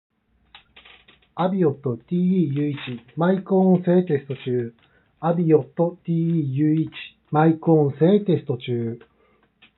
マイク性能は少しこもり気味
騒音でも声は聞こえるが少しこもったような声になっています。
✅静音（40dB前後）